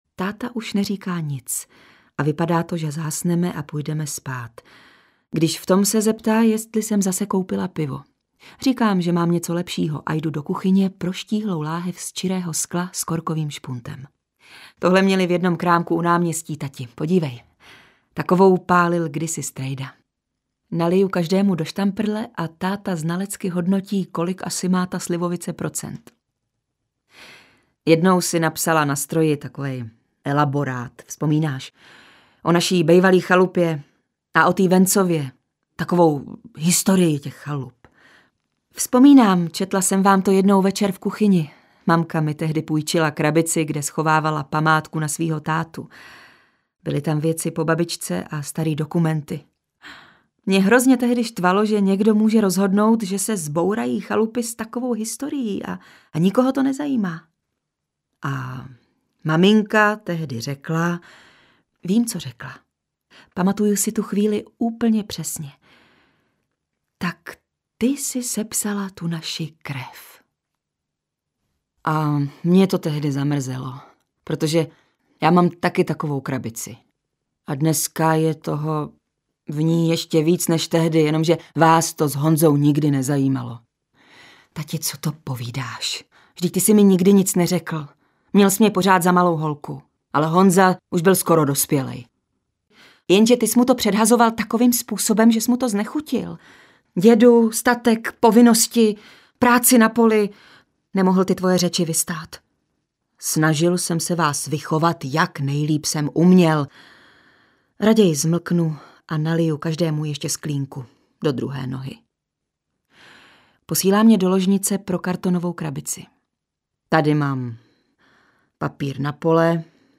Příběh služebnice - audiokniha obsahuje zfilmovaný anti-utopický příběh ze světa budoucnosti, který bojuje s ekologickými katastrofami a neplodností.
Čte Zuzana Kajnarová a Martin Myšička.